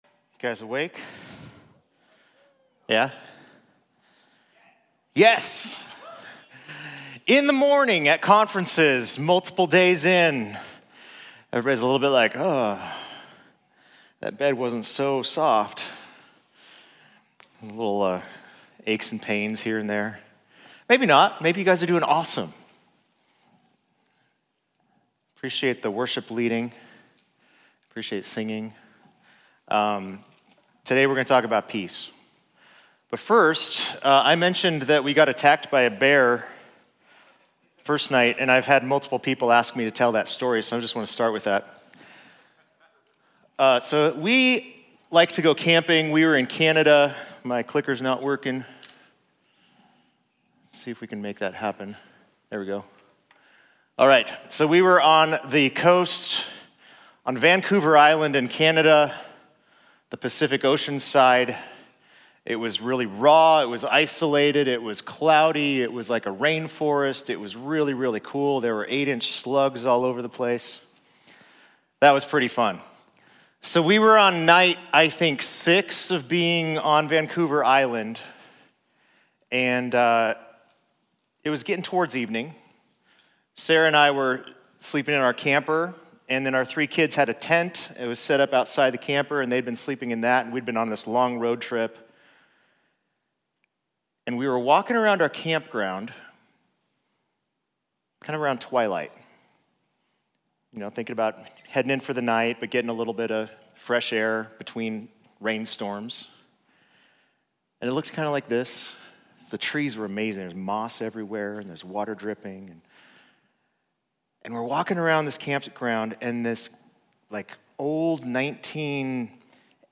Series: Live Full: Winter College & Young Adults Conference 2026